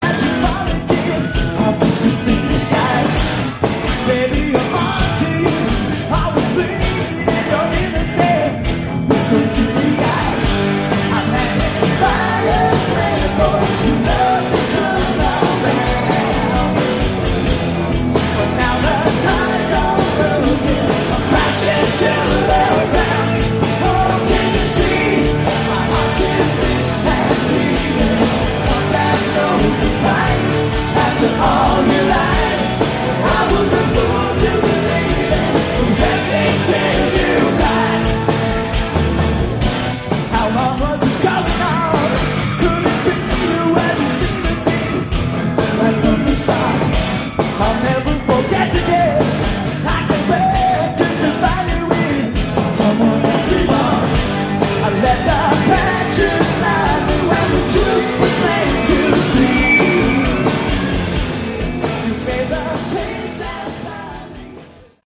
Category: AOR
lead vocals, guitar
lead vocals, bass
vocals, keyboards
drums
live